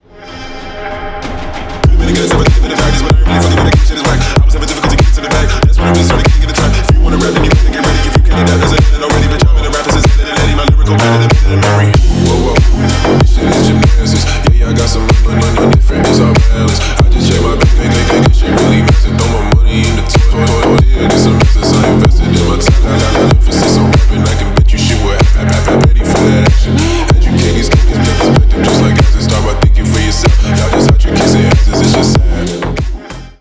бас , рэп , клубные , g-house